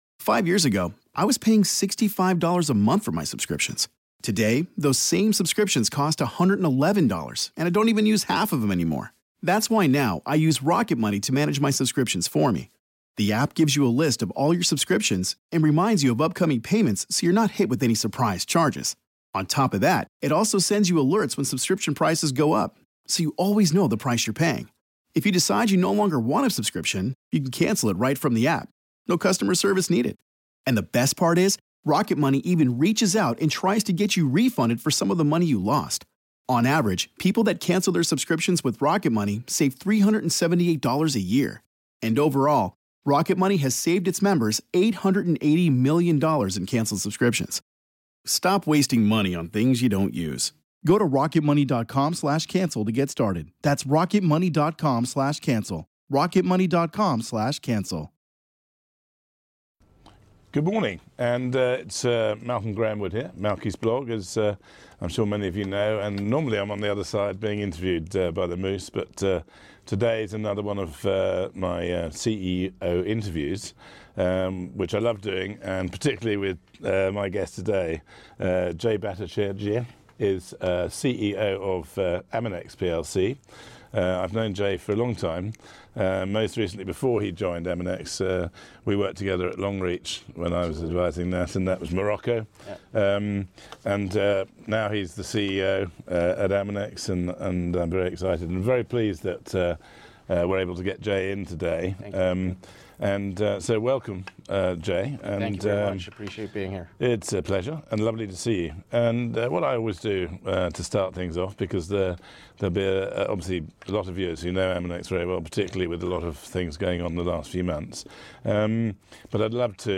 TipTV CEO Interview